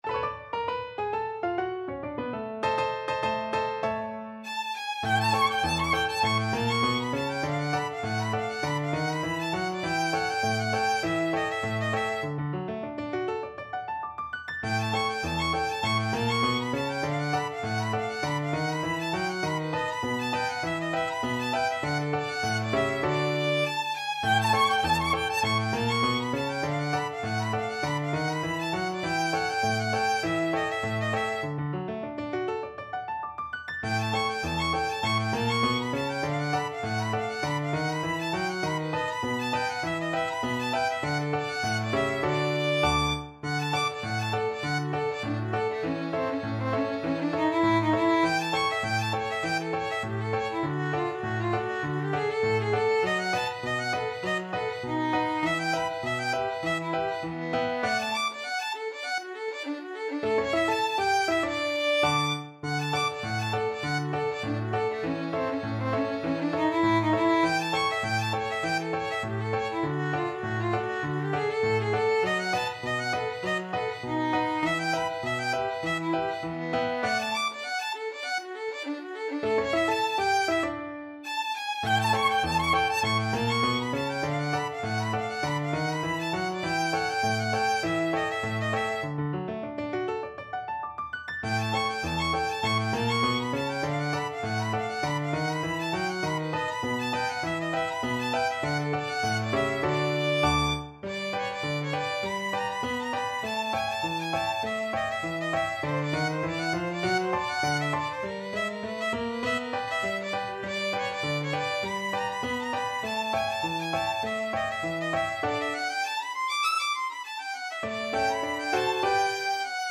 Violin
D major (Sounding Pitch) (View more D major Music for Violin )
2/4 (View more 2/4 Music)
Moderato